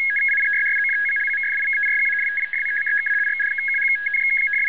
This signal is mainly 81 bd, pseudo random, one or two characters, 12 bits, usually encrypted.
It is mainly a 2 ch system but there is a 40.5 bd signal that is a 1 ch variant. Most commonly found baud rates are 36.5, 40.5 for the 1 ch version and 73 and 81 for the 2 ch version.